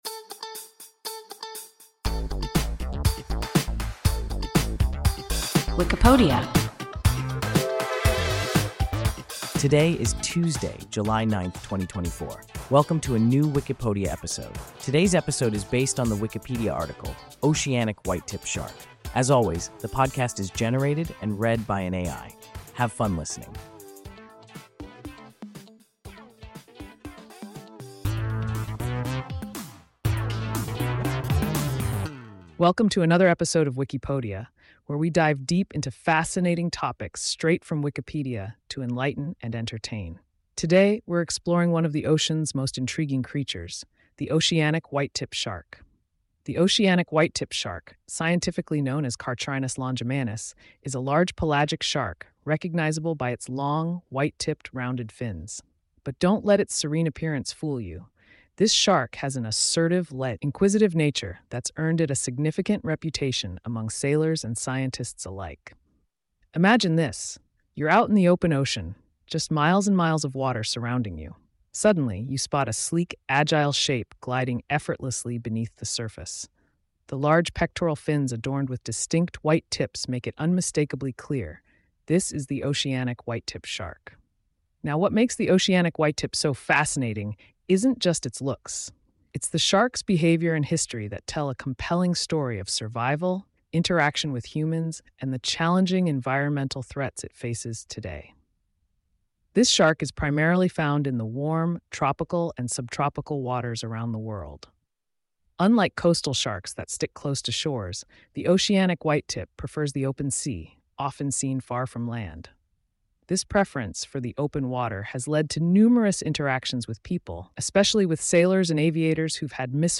Oceanic whitetip shark – WIKIPODIA – ein KI Podcast